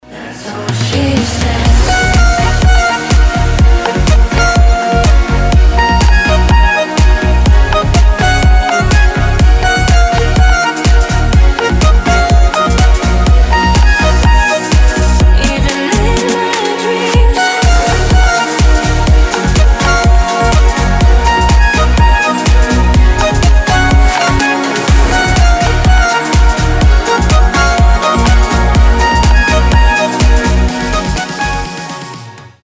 • Качество: 320, Stereo
dance
красивая мелодия
аккордеон